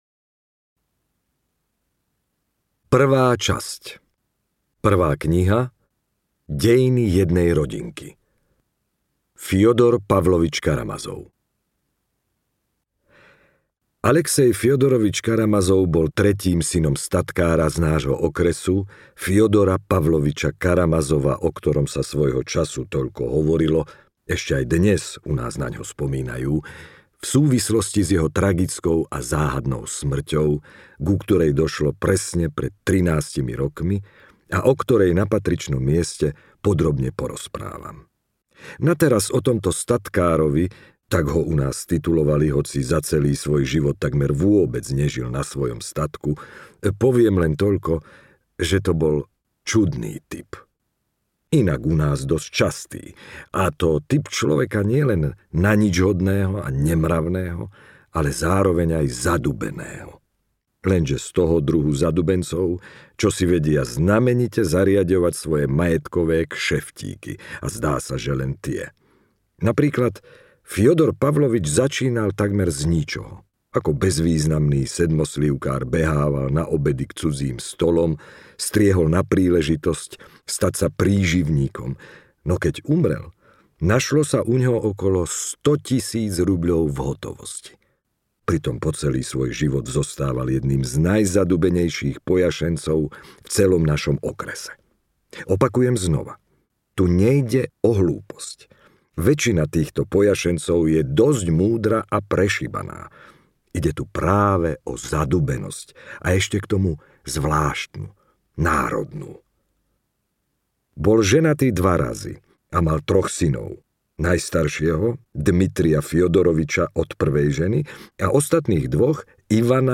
Bratia Karamazovovci audiokniha
Ukázka z knihy
bratia-karamazovovci-audiokniha